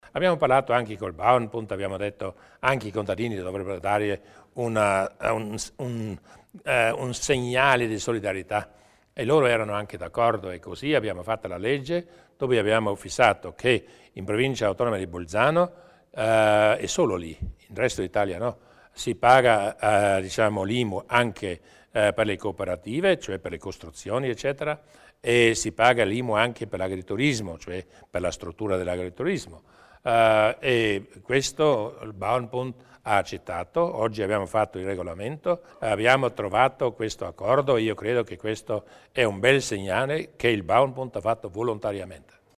Il Presidente Durnwalder spiega i cambiamenti in termini di IMU per gli agricoltori